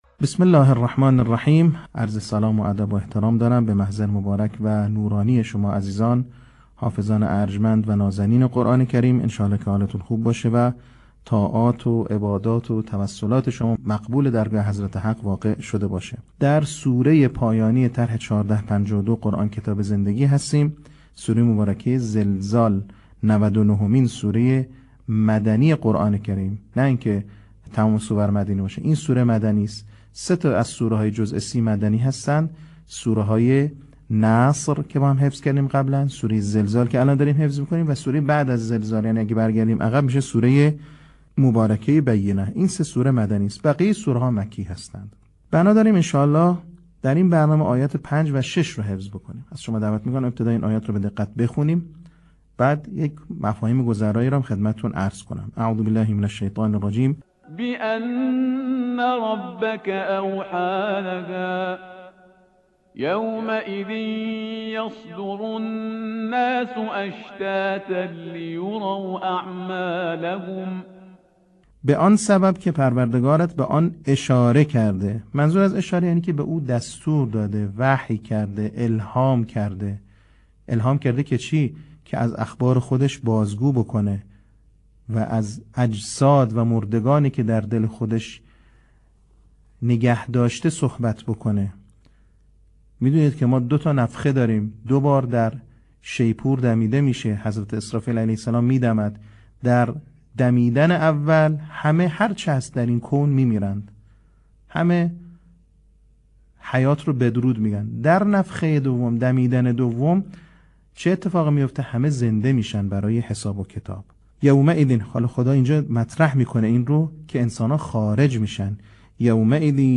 صوت | بخش سوم آموزش حفظ سوره زلزال